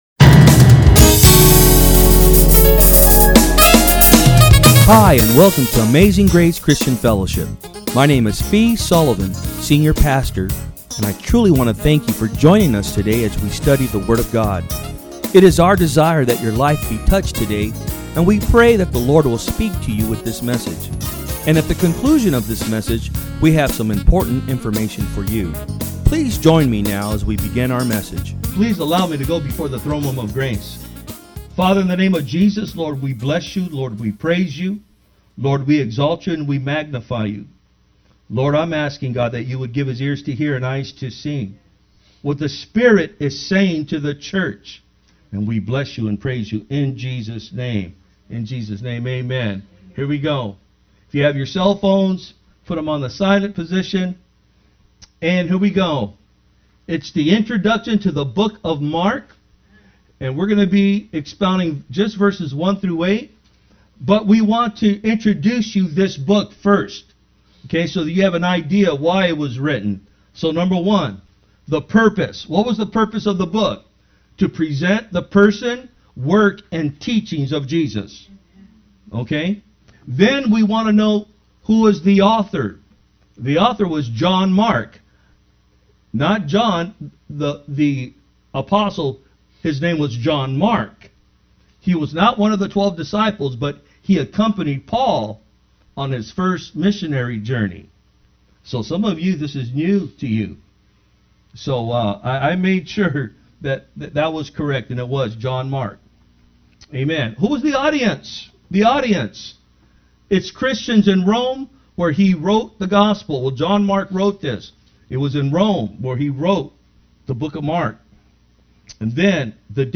Sermons
From Service: "Sunday Am"